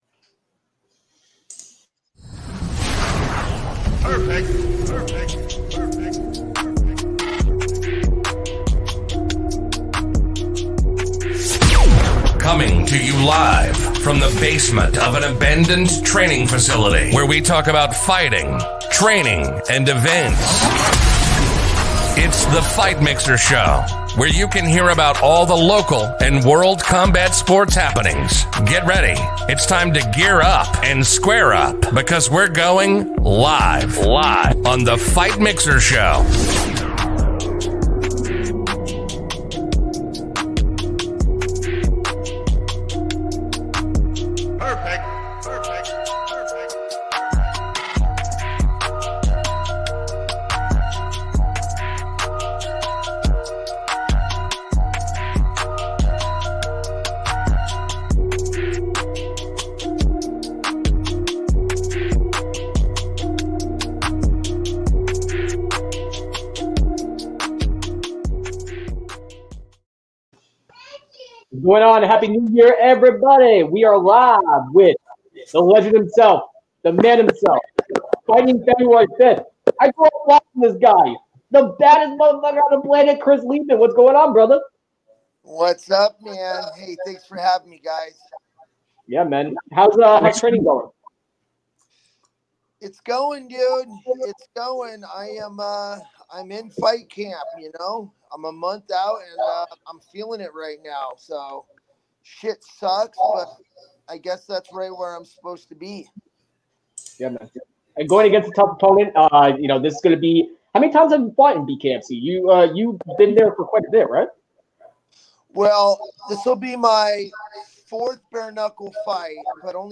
BKFC-Fighter-Chris-Leben-Interview.mp3